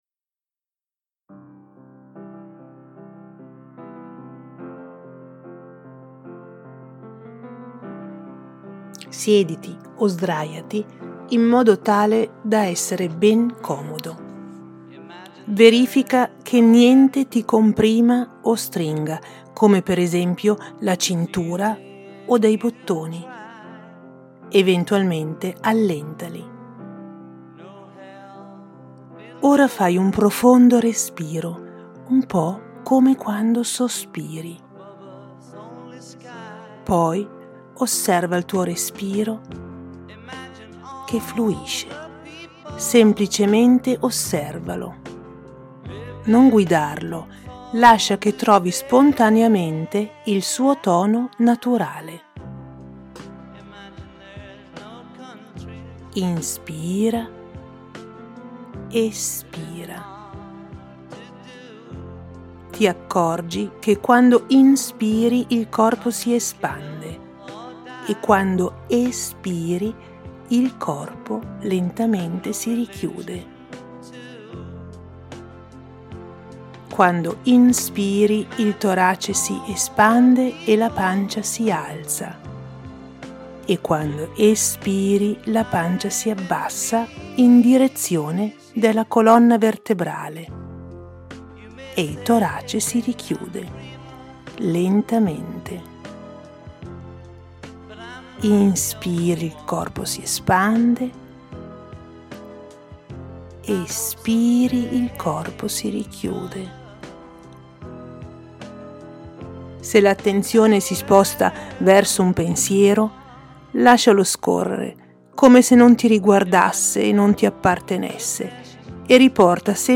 il rilassamento